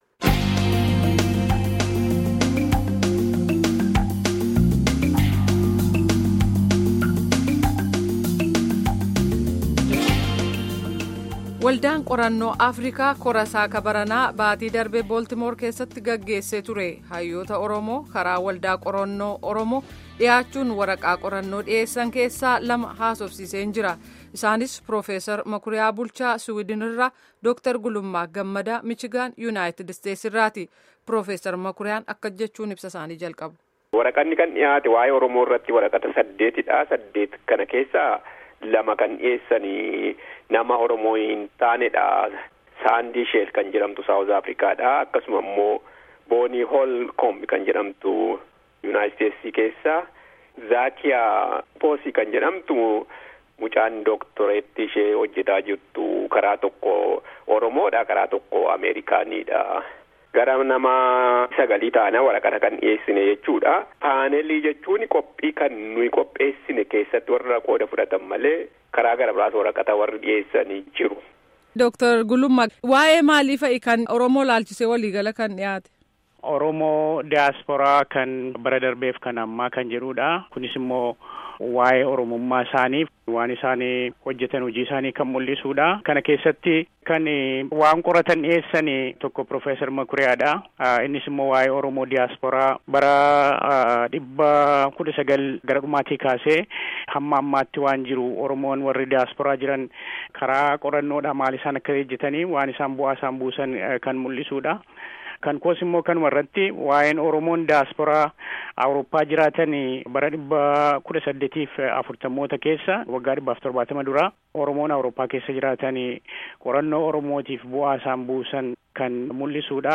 Gaafii fi deebii